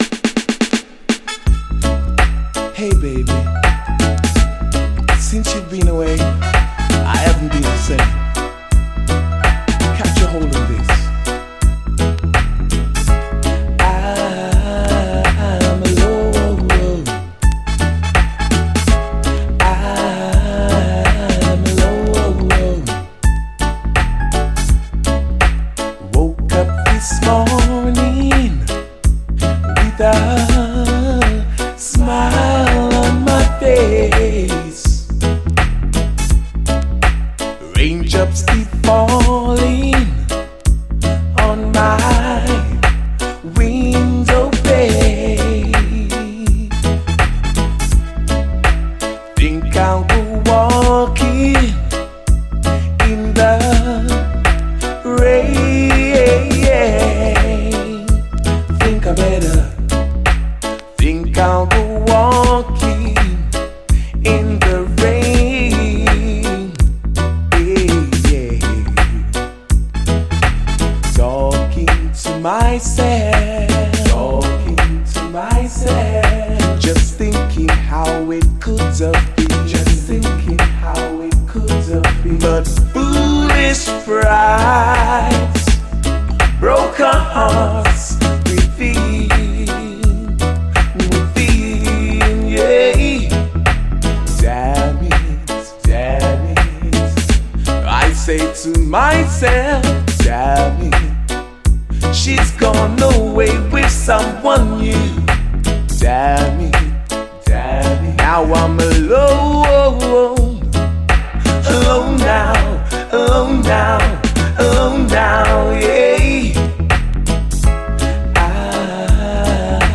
REGGAE
インスト、ダブも収録。